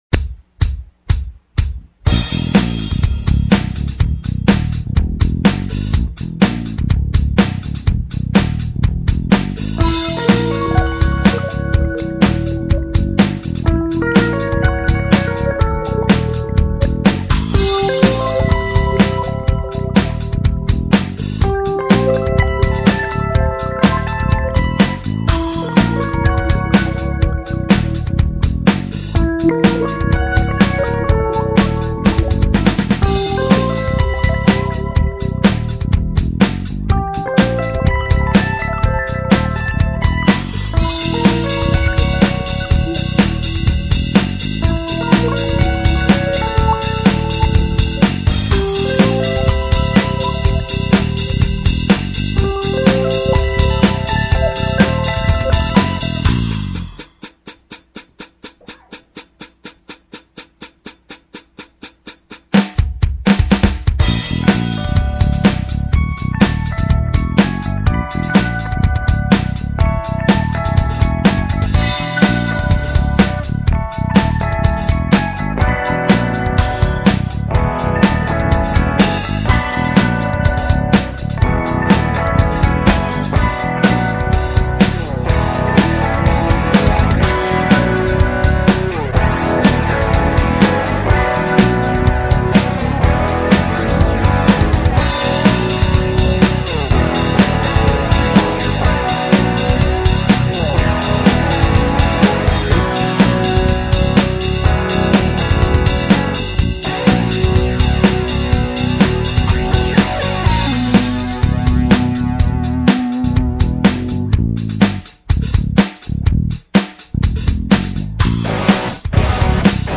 bass and drums
guitar
There’s at least some bitonality at work in the opening section, but I think it sounds fine—weird, maybe, but deliberately weird.